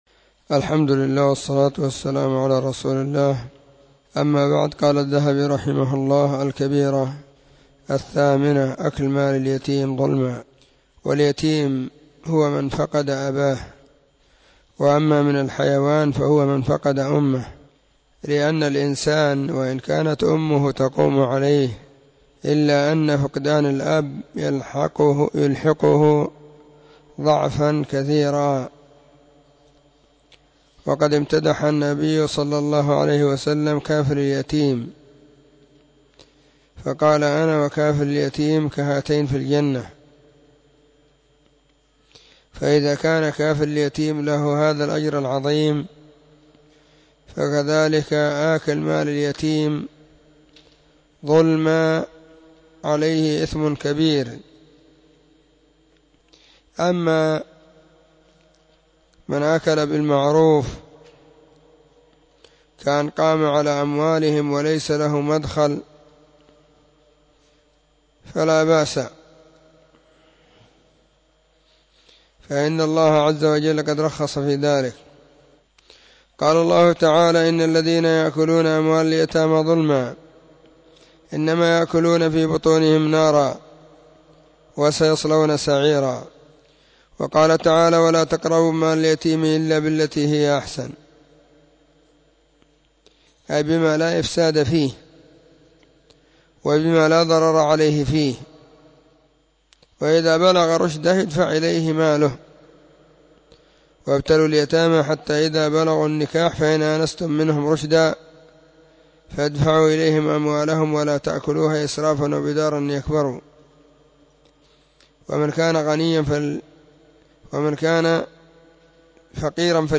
📢 مسجد الصحابة – بالغيضة – المهرة، اليمن حرسها الله.…
🕐 [بين مغرب وعشاء – الدرس الثاني]